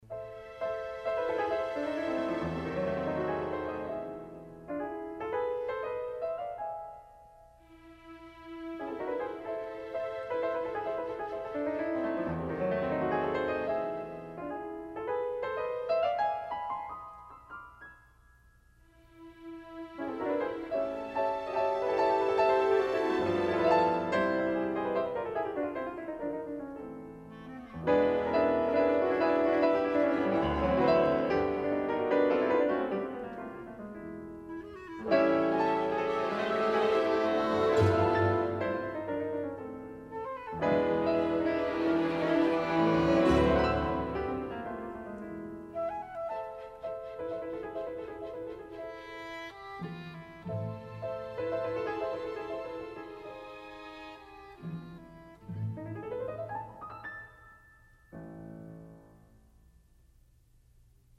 速度相同，钢琴以华彩技巧奏主题变奏，英国管优美的加入两次主题片断